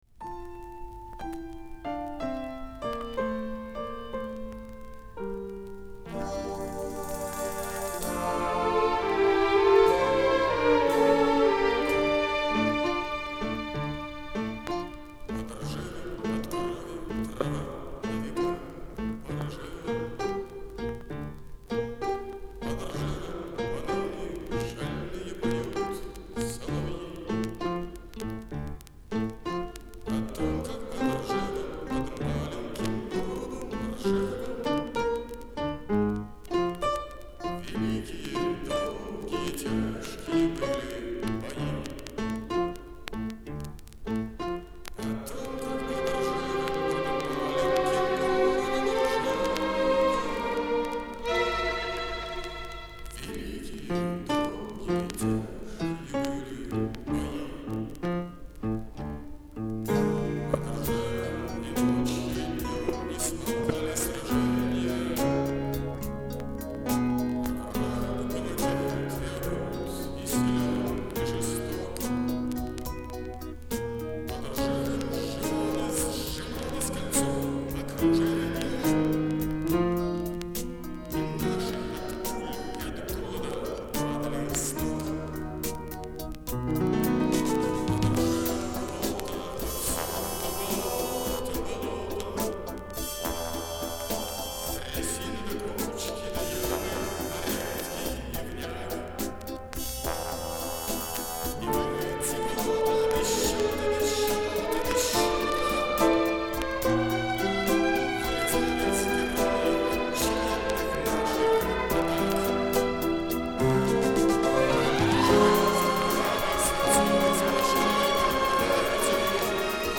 На слух вроде не прослушиваются такие искажения.